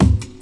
Instrument 00 is the kick and Instrument 01 is the snare.